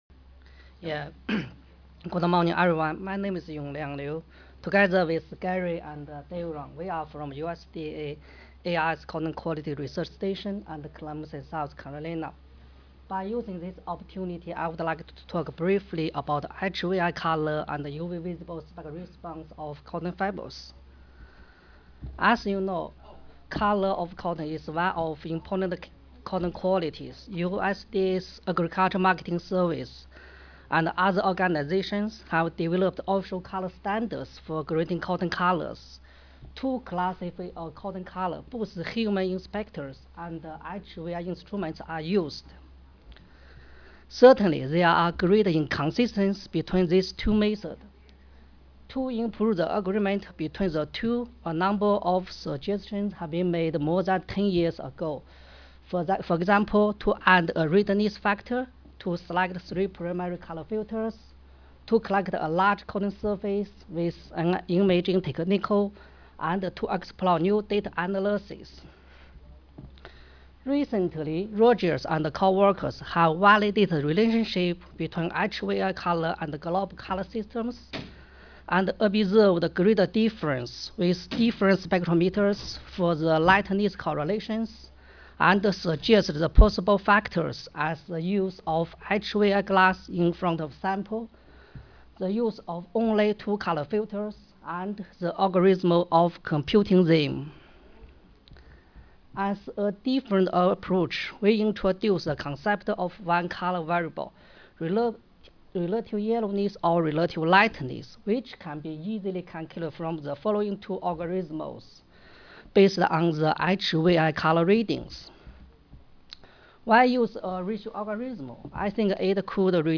Cotton Quality Measurements - Thursday Morning Session
Audio File Recorded presentation